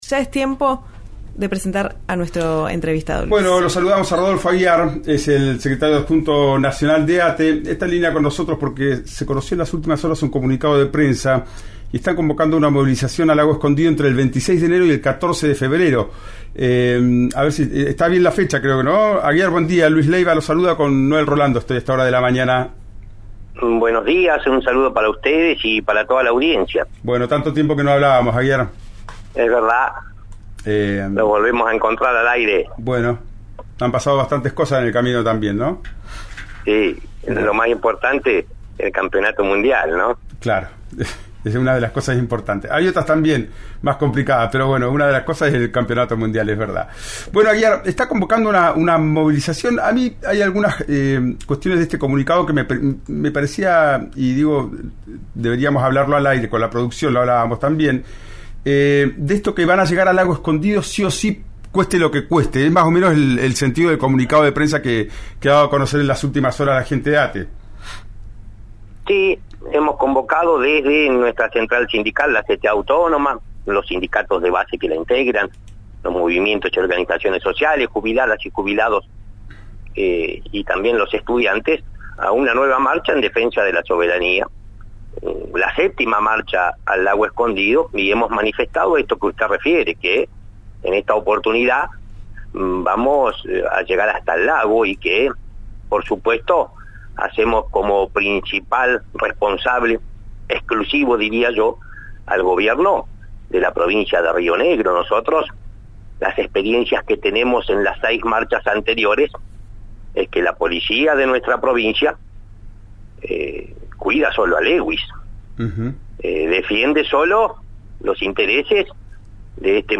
También habló con 'Ya es tiempo' por RÍO NEGRO RADIO.